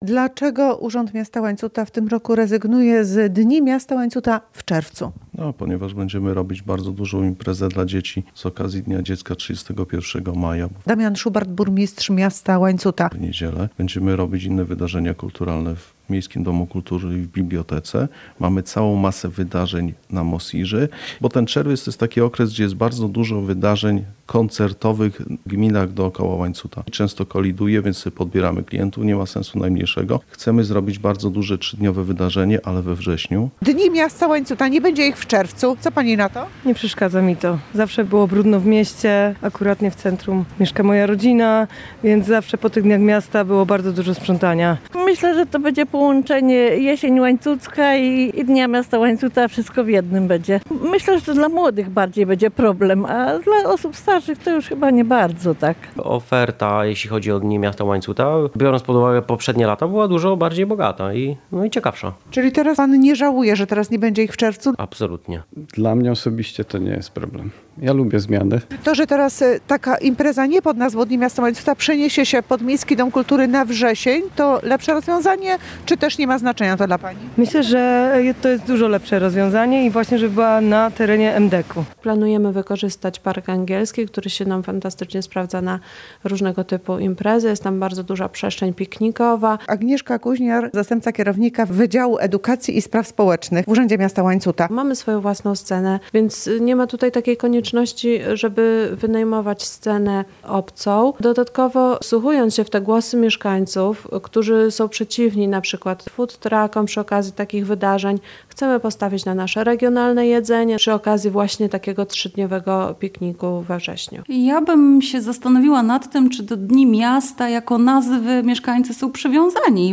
Zmiana terminu wydarzenia • Relacje reporterskie • Polskie Radio Rzeszów